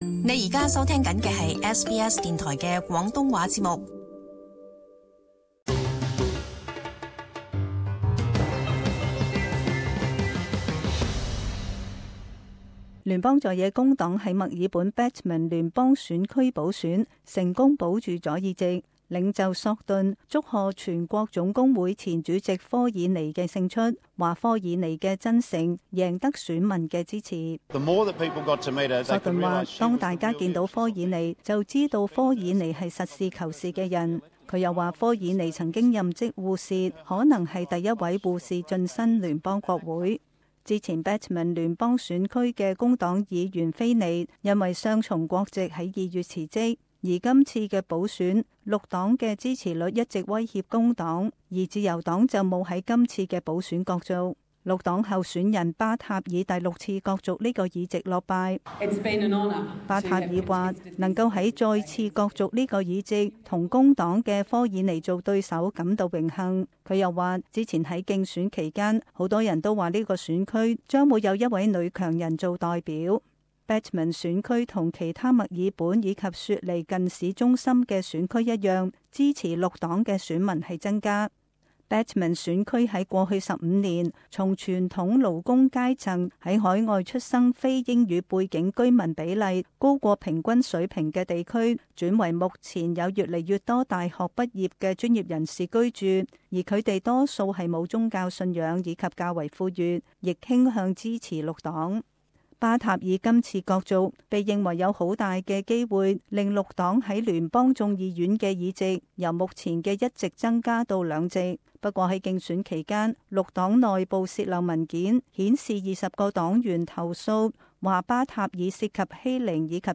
【时事报导】联邦工党保住Batman议席